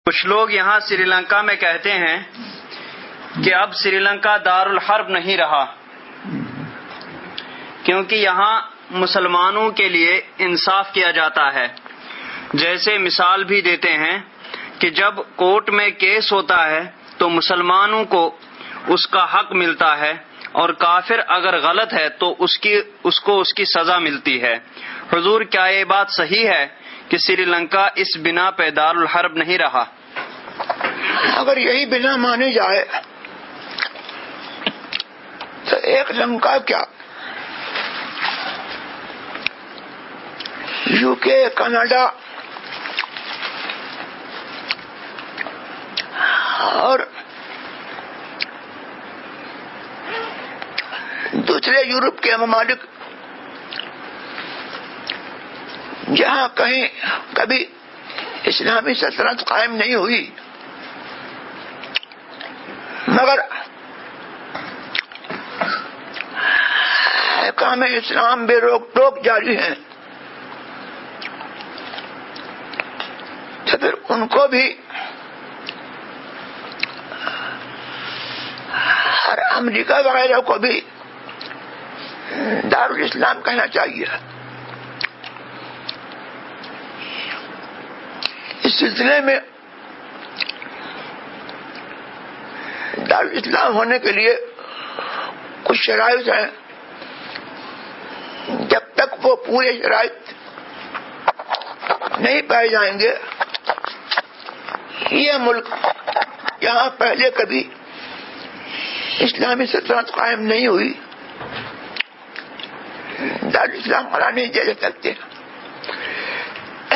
Answer (Voice Recording):